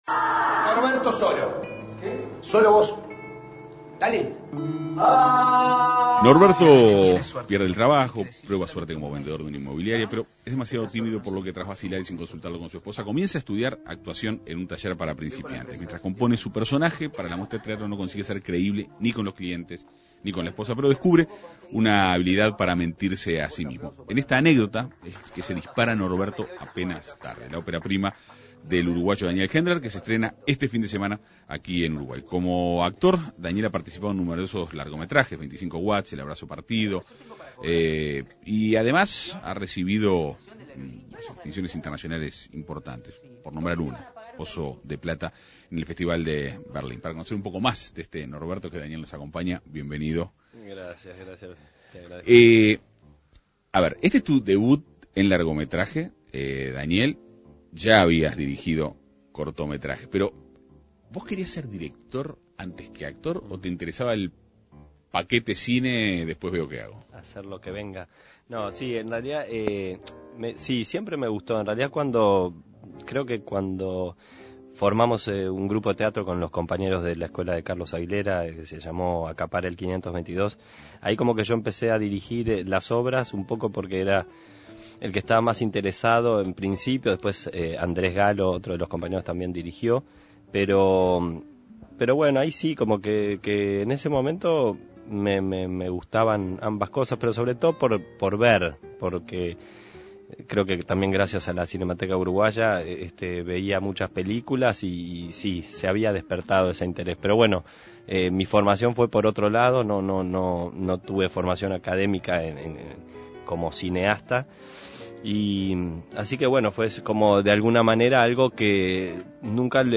Norberto, apenas tarde es la ópera prima del actor uruguayo Daniel Hendler. El director conversó en la Segunda Mañana de En Perspectiva.